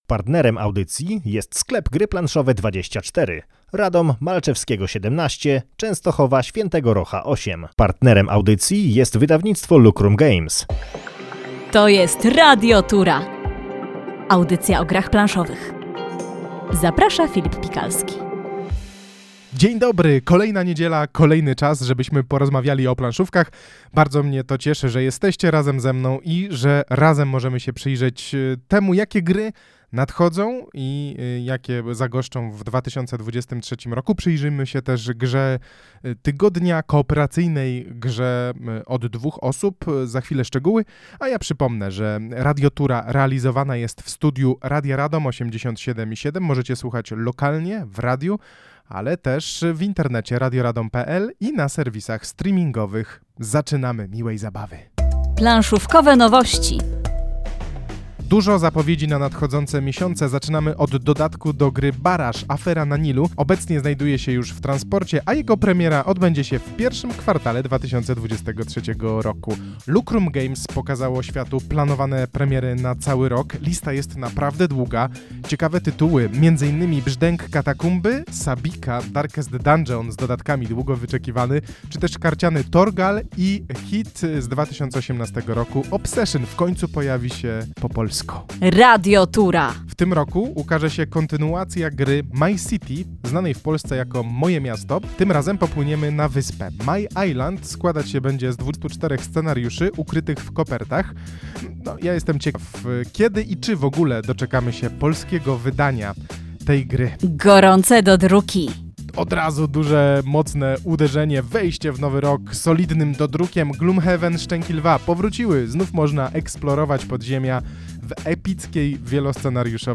Audycja o grach planszowych w Radiu Radom na 87,7 FM. Recenzja gry Gra dla młodych buntowniczek.